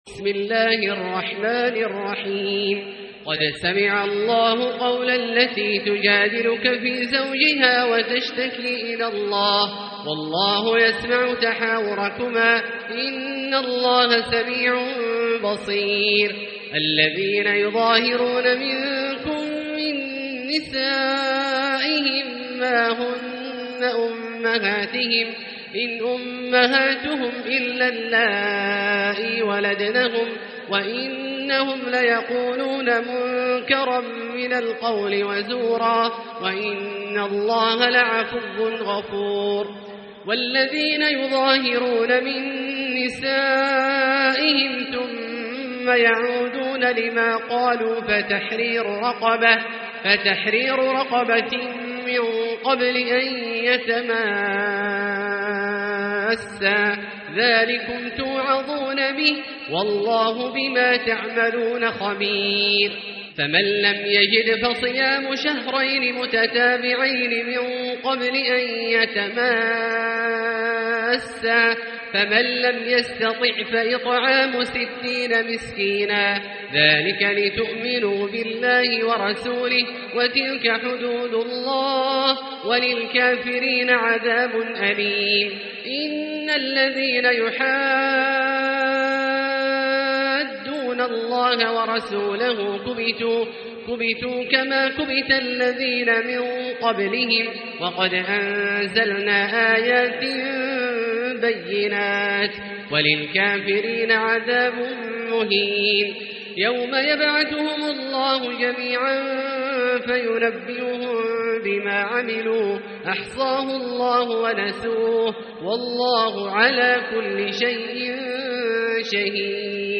سورة المجادلة | مصحف الحرم المكي ١٤٤٤ > مصحف تراويح الحرم المكي عام ١٤٤٤ > المصحف - تلاوات الحرمين